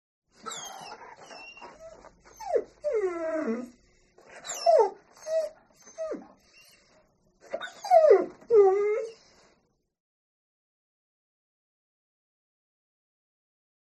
دانلود صدای گریه سگ از ساعد نیوز با لینک مستقیم و کیفیت بالا
جلوه های صوتی